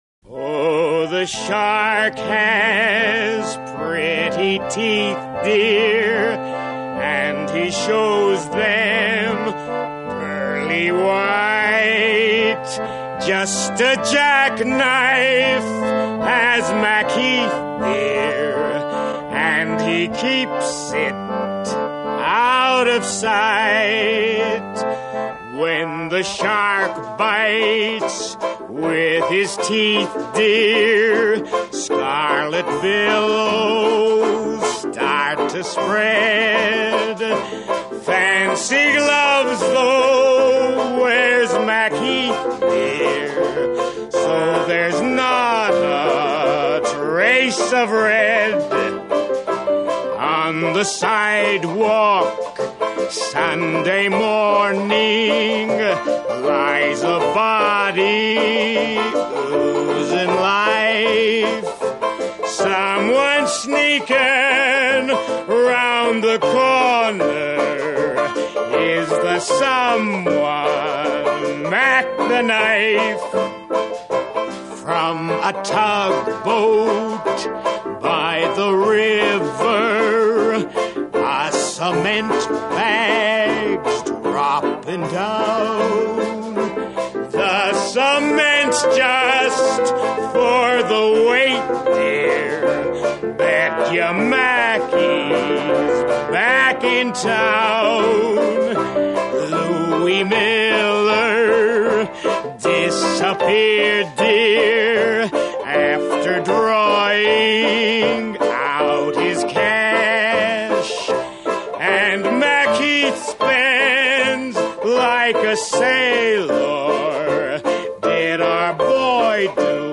BRECHTIAN SINGING